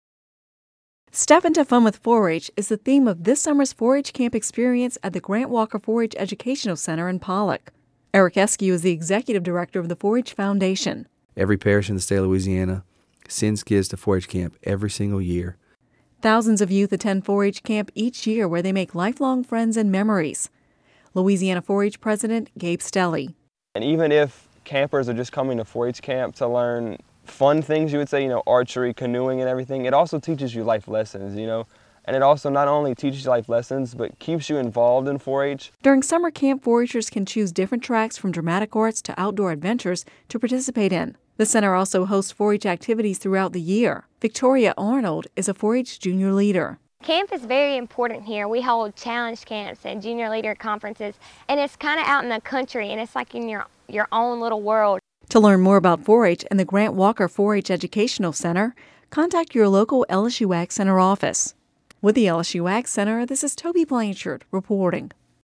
(Radio News 04/04/11)